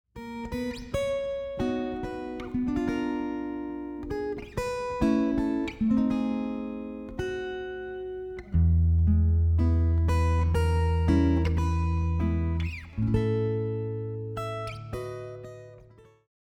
Get the full guitar audio track